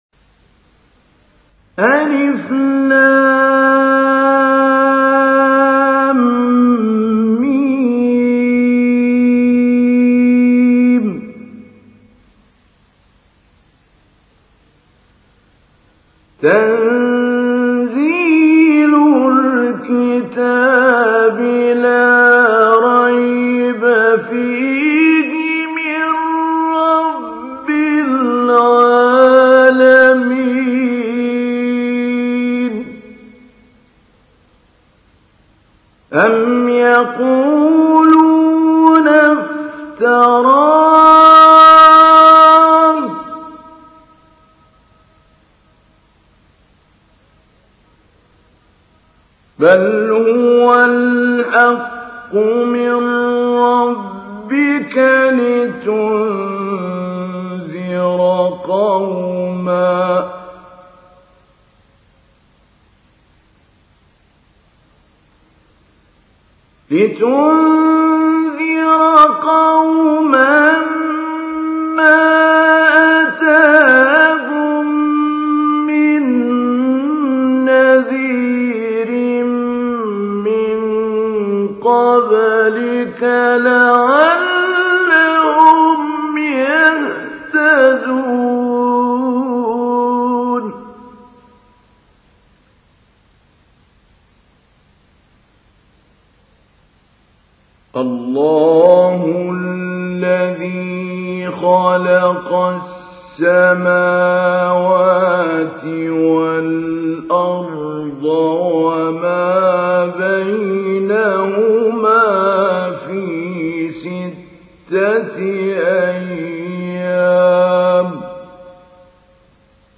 تحميل : 32. سورة السجدة / القارئ محمود علي البنا / القرآن الكريم / موقع يا حسين